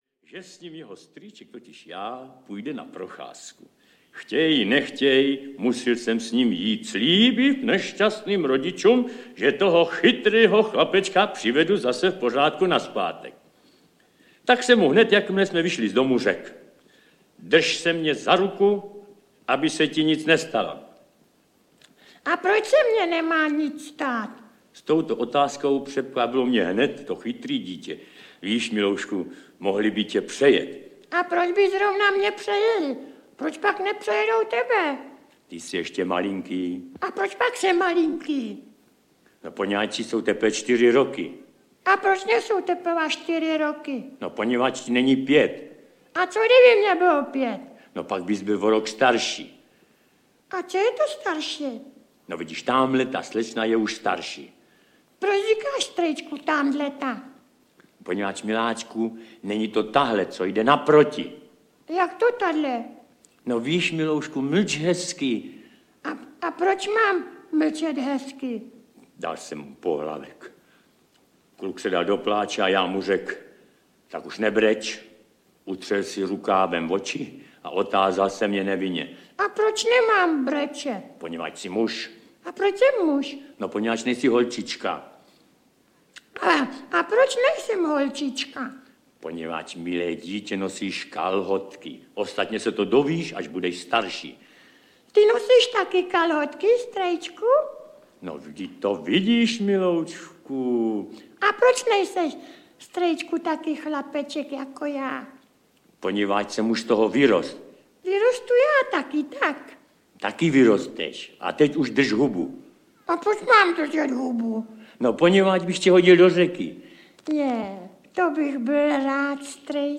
Výběr z díla světově proslulého spisovatele audiokniha
Ukázka z knihy
• InterpretVlasta Burian, Jan Werich, Jiřina Šejbalová, František Filipovský, Vlastimil Brodský, Emil Kadeřávek, Petr Nárožný, Viktor Preiss, Alois Švehlík, Ferenc Futurista, Jaroslav Marvan, Josef Lada, Rudolf Hrušínský, Josef Somr, Vojtěch Dyk, Miroslav Táborský,